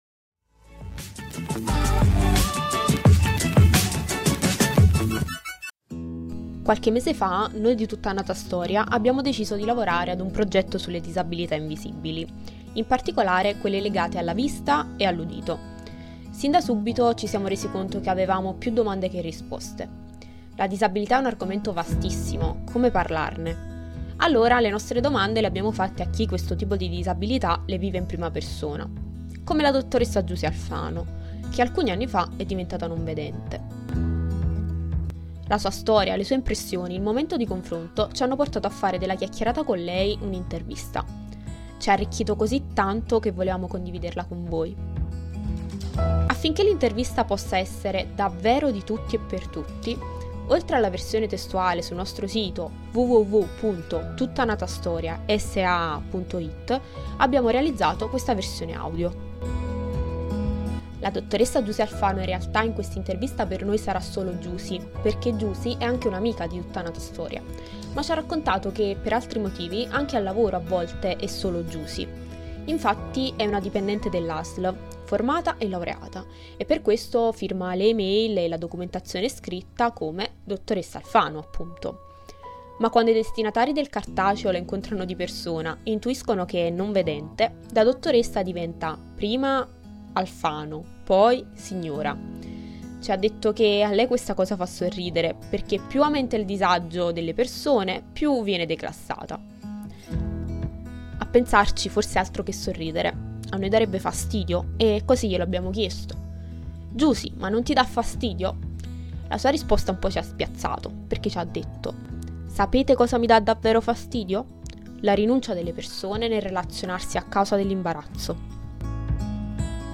audio-intervista.mp3